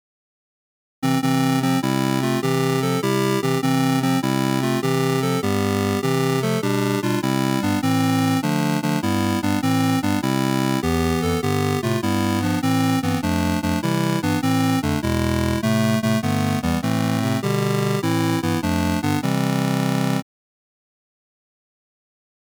Synth-Wave